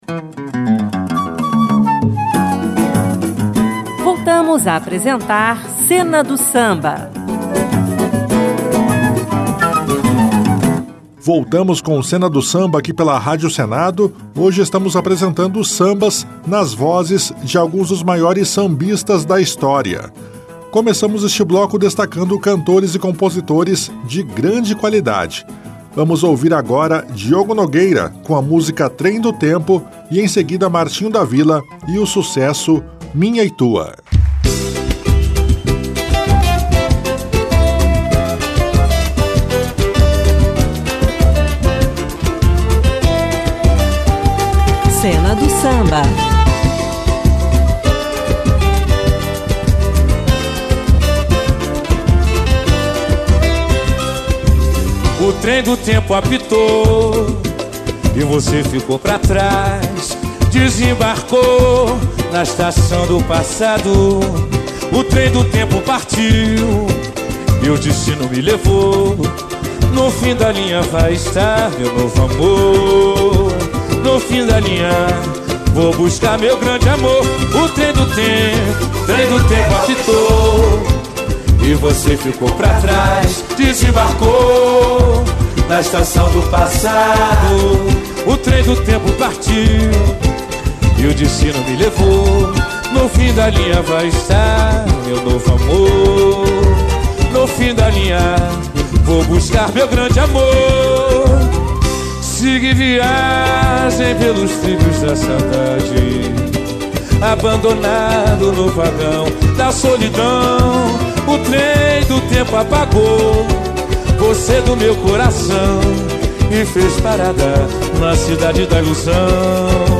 além de grupos de samba e pagode.